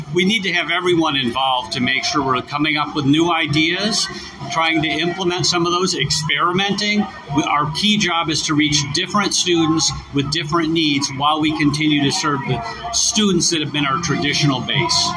IUP kicked off the academic year with a program this morning at Fisher Auditorium.